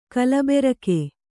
♪ kalaberake